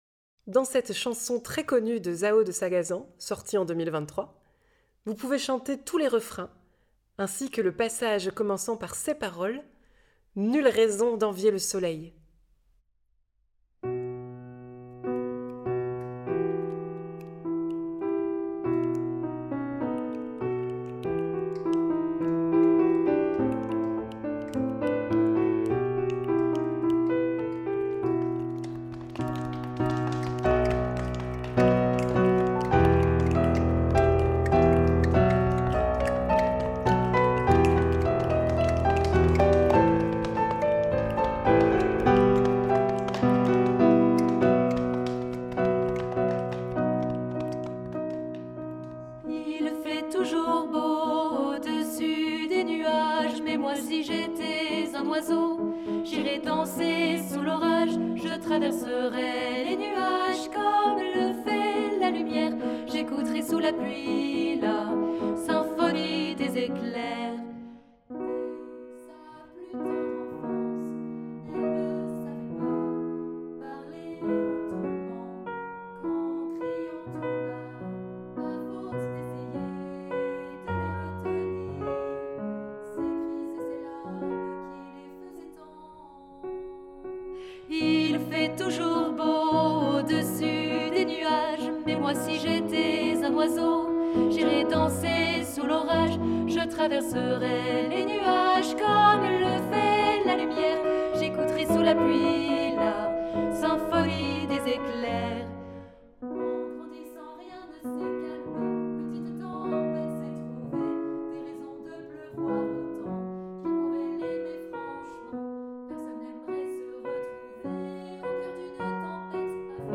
Genre :  Chanson
Version tutti choeur participatif